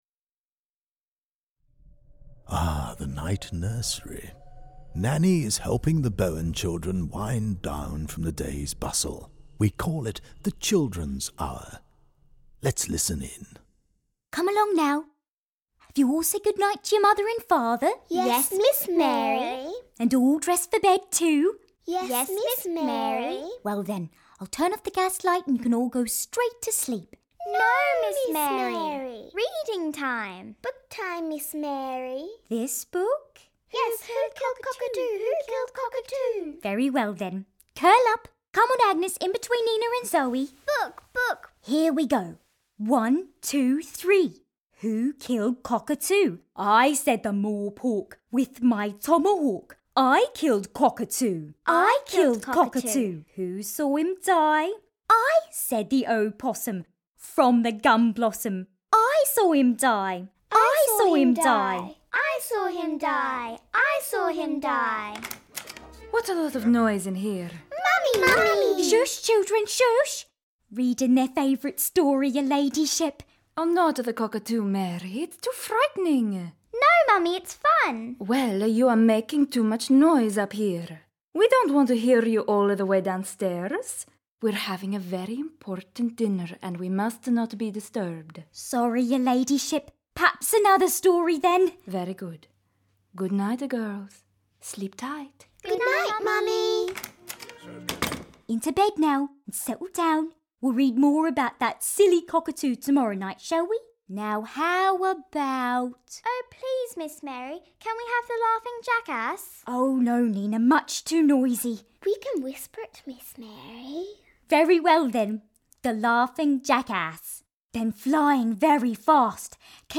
Led by the ghost of Governor Musgrave, who died in the House, the podcast tour guides you through key moments in the life of the House with audio dramatisations of exchanges that would have occurred between the characters of 19th century Queensland.